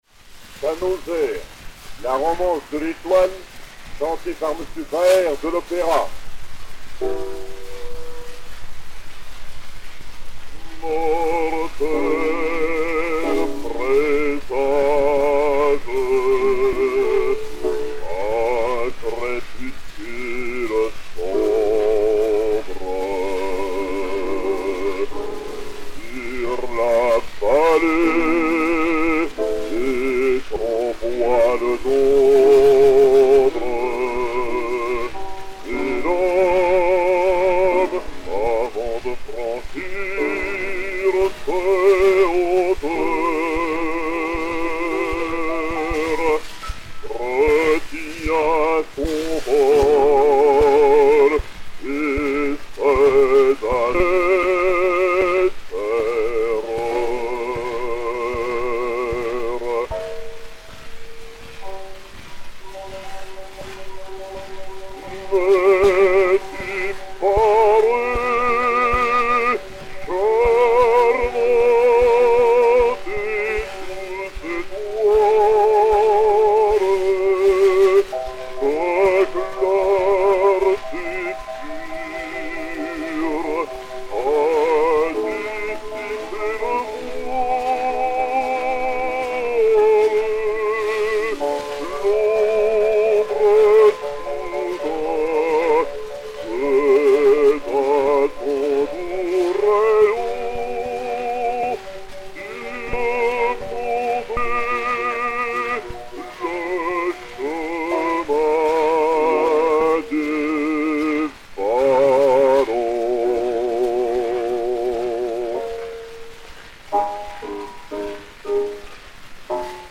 basse française
et Piano
Zonophone X 2170, enr. à Paris vers 1903